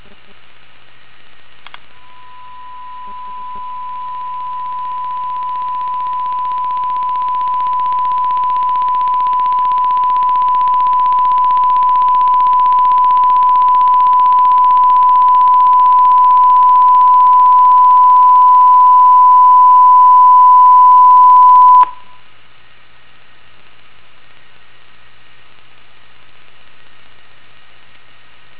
基本上、似乎我在 IN1L 和 IN2L 上采样的数字化音频上有一个快速咔嗒声。
附件中有三个音频文件、我的麦克风在其中录制1kHz 的声音。
在这三种模式中、都是1kHz 的记录。
所有三个录音都是通过使用扬声器旁边的麦克风播放我的 PC 扬声器的1kHz 声音来完成的。
文件3： RAMPING_1kHz.WAV
RAMPING_5F00_1kHz.WAV